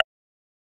key-movement.ogg